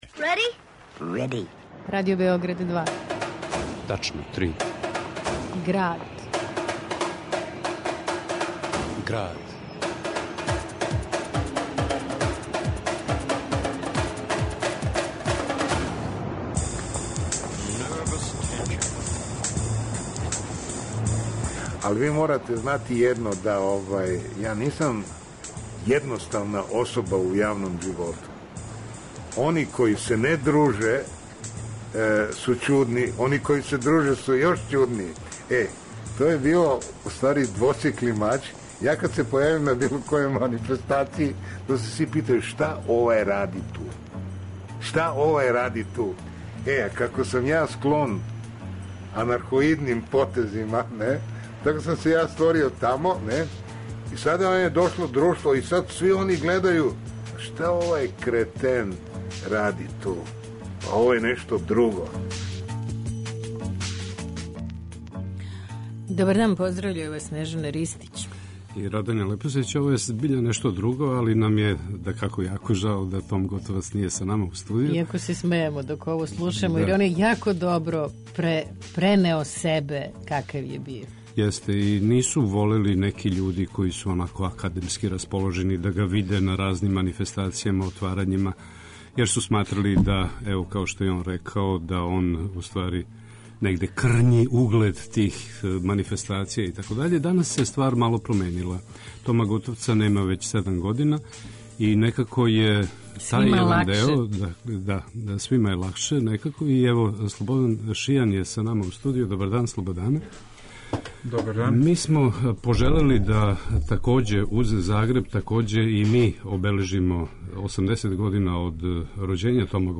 У Граду - уз инсерте из бројних интервјуа са Томиславом Готовцем (1937-2010) - филмски редитељ Слободан Шијан, аутор књиге Кино Том (2013), говори о пријатељству између њих двојице, о Томовим перформансима који су увек нервирали јавност, о промењеном односу према уметнику кад га више нема...